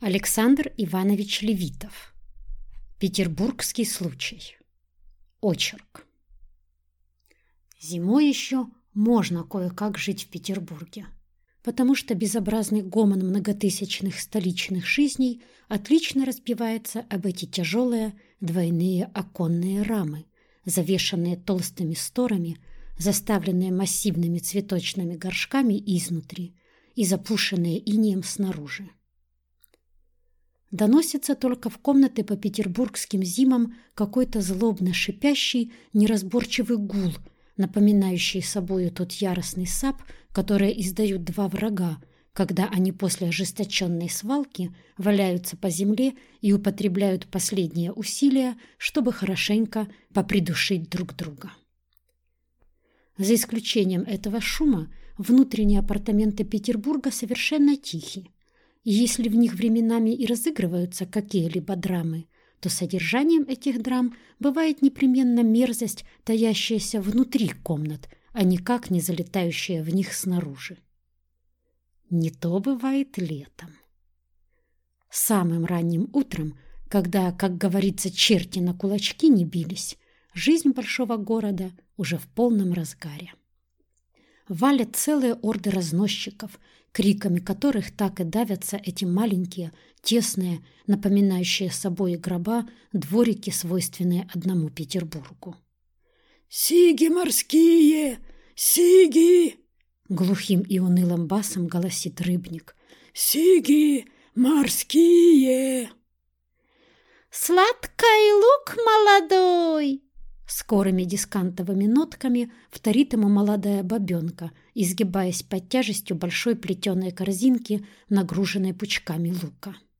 Аудиокнига Петербургский случай | Библиотека аудиокниг